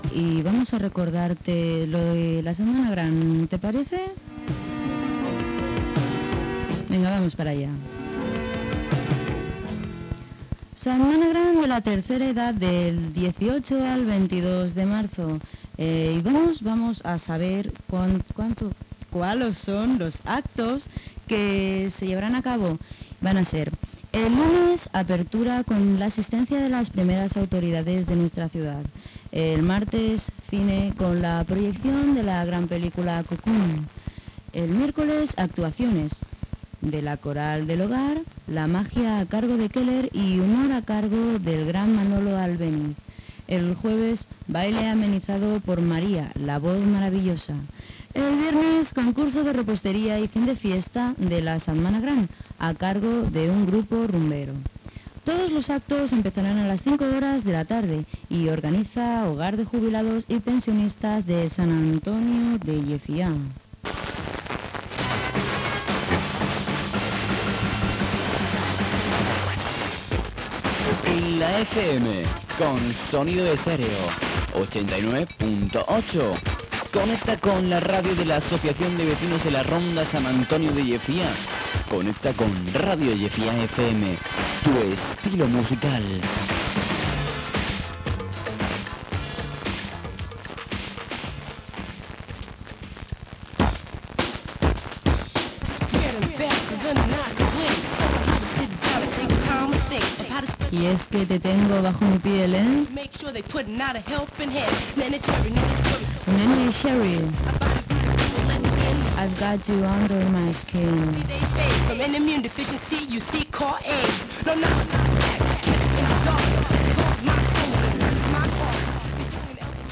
indicatiu i tema musical